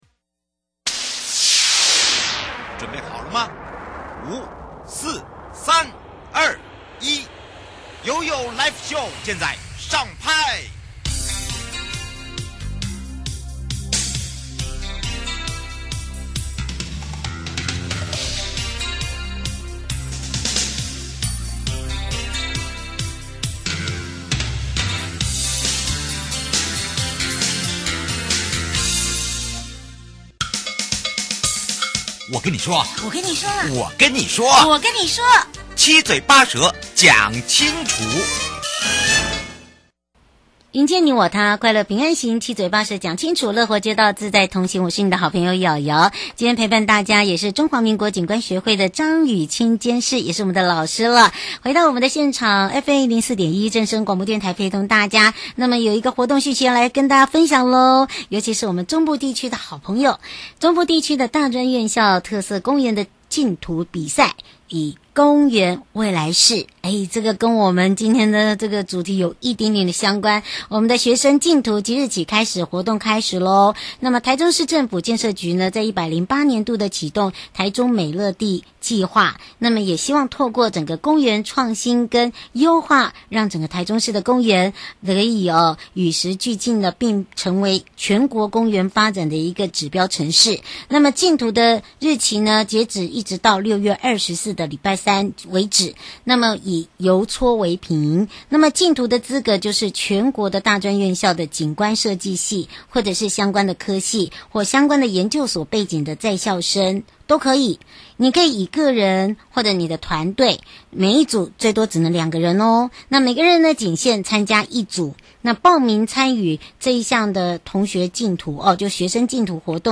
受訪者： 營建你我他-快樂平安行-七嘴八舌講清楚- 無障礙/通用設計理念的推廣與落實說明 節目內容： 中華民國